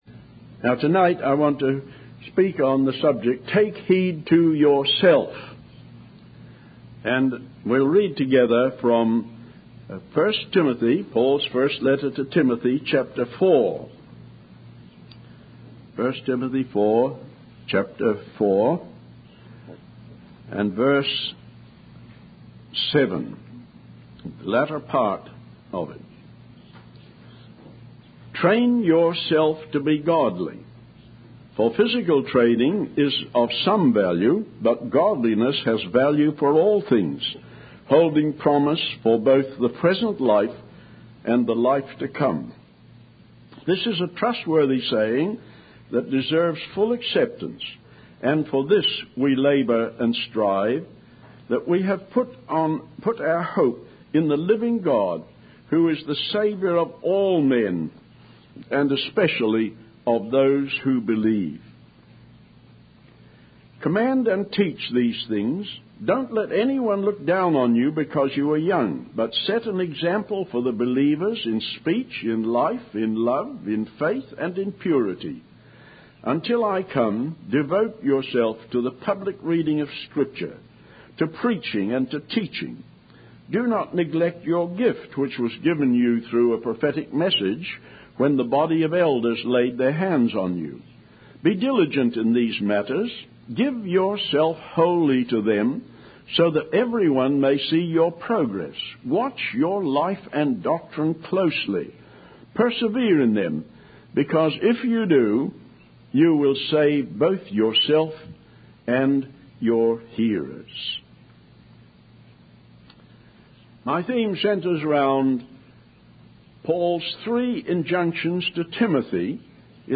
In this sermon, Billy Graham reflects on the life of a man who dedicated himself to one thing rather than dabbling in many things. He emphasizes the importance of finding the one thing that unifies and integrates our lives, and giving the best of ourselves to God.